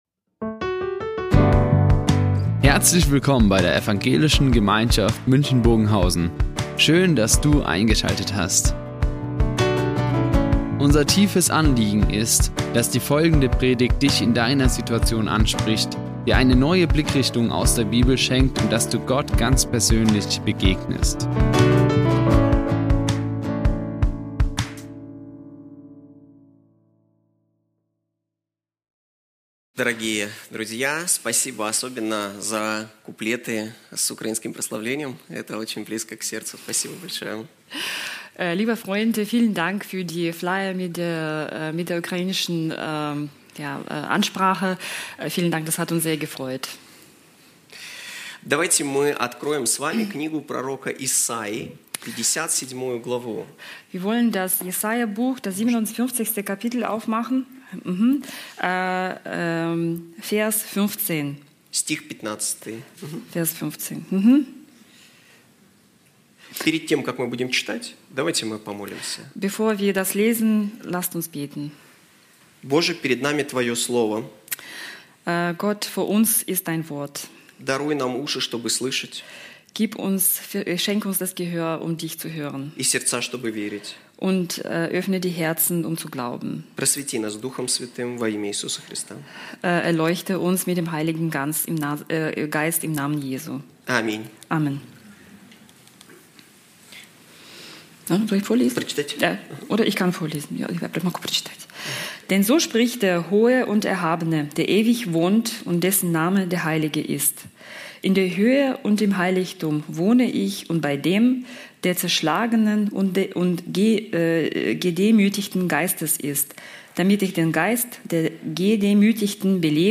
Die Aufzeichnung erfolgte im Rahmen eines Livestreams.